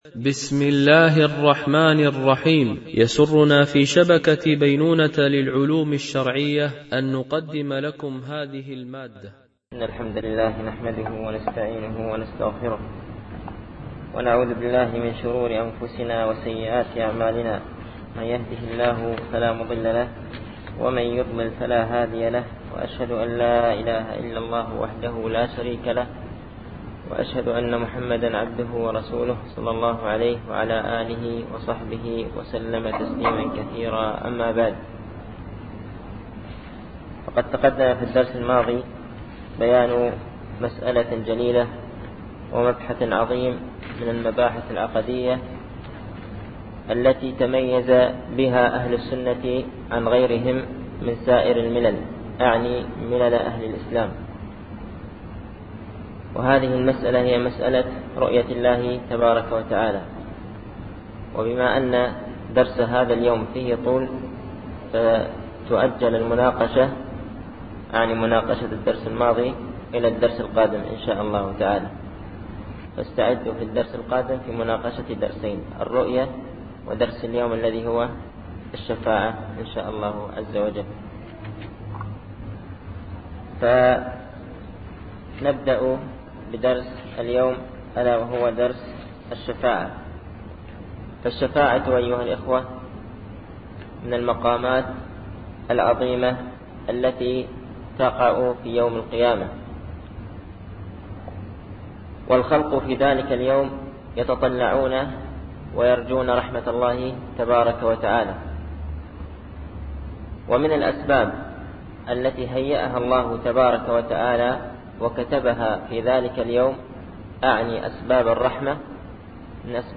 الألبوم: شبكة بينونة للعلوم الشرعية التتبع: 52 المدة: 63:48 دقائق (14.64 م.بايت) التنسيق: MP3 Mono 22kHz 32Kbps (CBR)